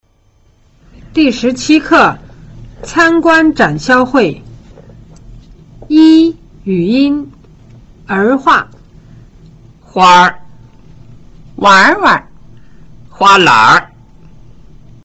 普通話中有一部分的詞(特別是名詞)帶有輔助的發音。這是在詞的韻尾與 音節的韻母 “er” 結成拼合  化合的關係而產生的 音變,使兩個音節融合成一個音節, 這種語音現象稱為 兒化 漢語拼音書寫時,只需在音節後加上r即可。這種兒化音是普通話語音的特點之一。比如 : 小孩兒、唱歌兒等等。